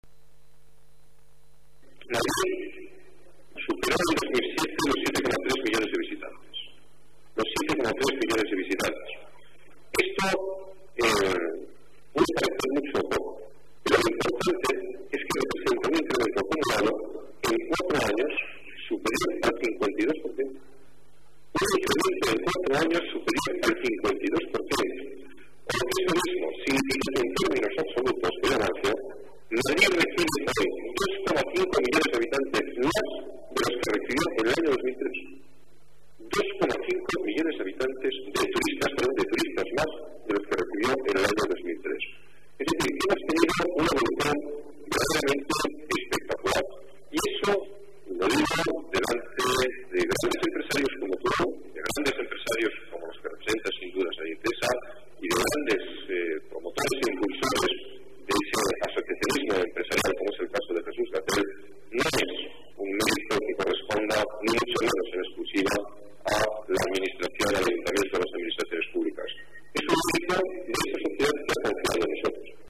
Nueva ventana:Declaraciones del alcalde sobre el crecimiento de Madrid, durante su visita al hotel Eurostars